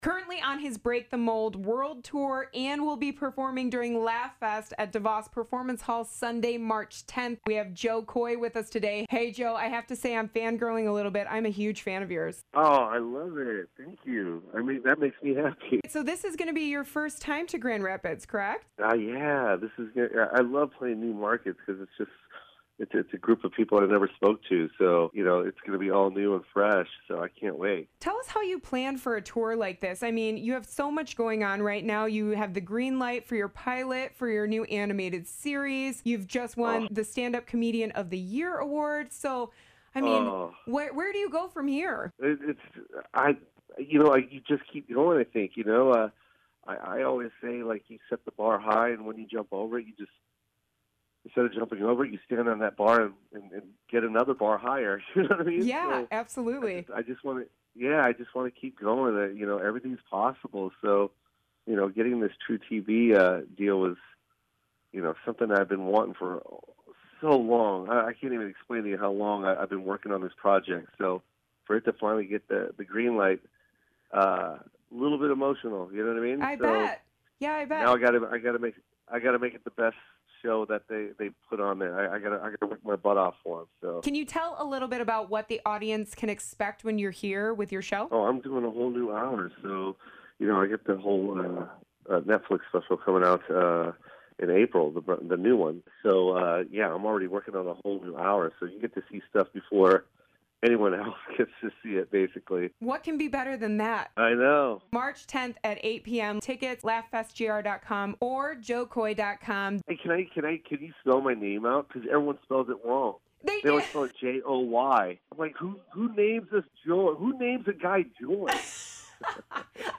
Jo-Koy-Laugh-FestInterview.mp3